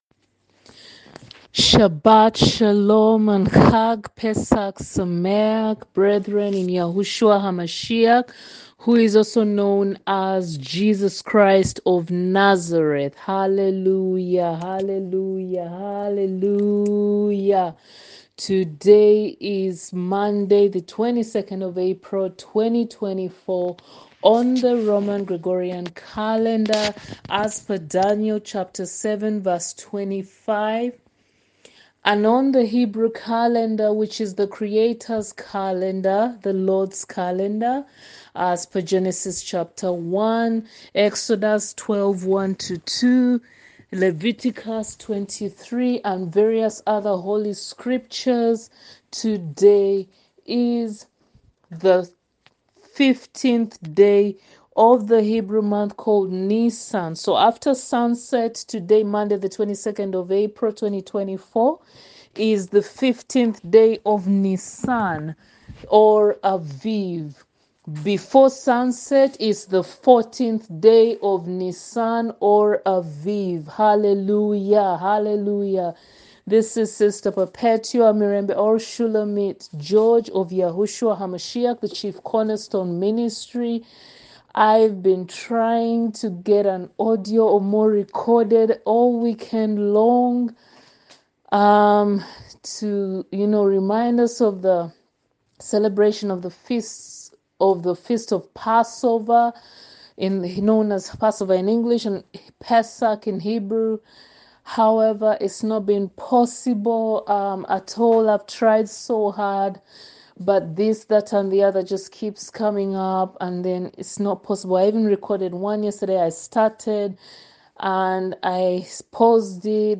Audio Ministration